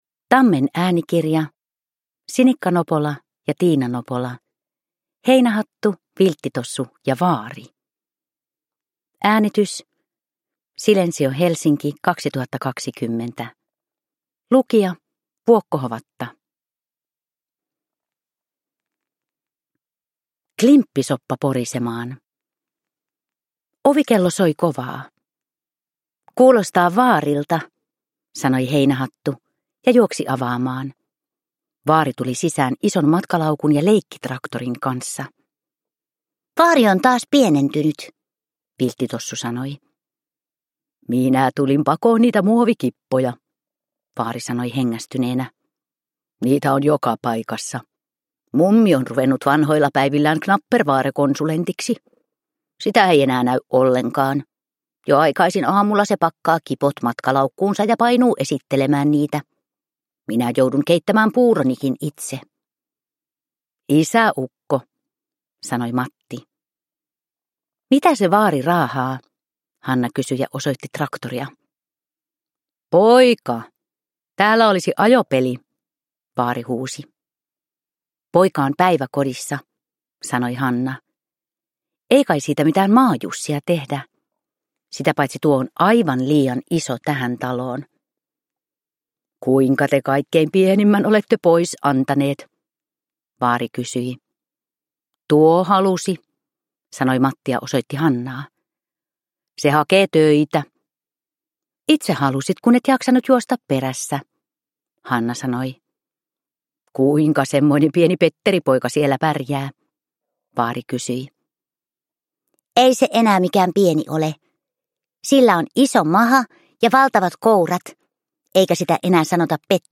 Heinähattu, Vilttitossu ja vaari – Ljudbok – Laddas ner